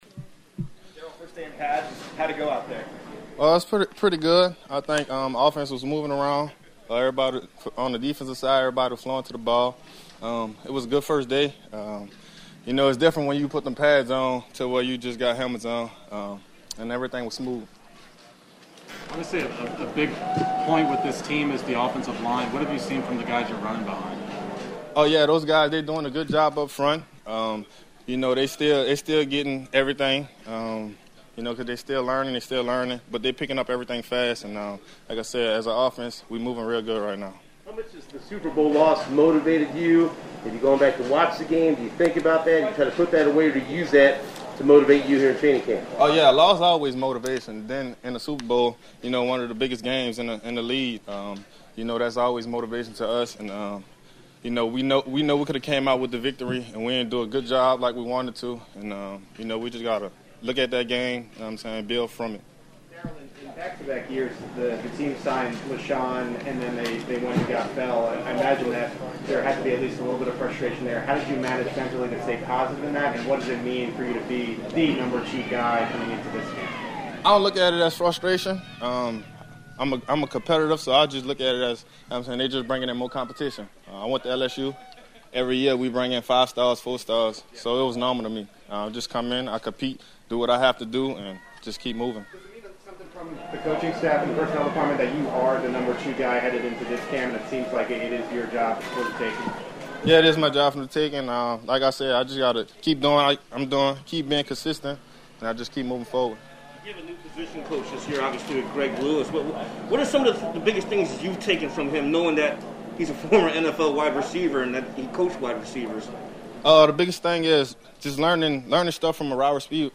Darrel Williams visits with the media after Tuesday’s practice.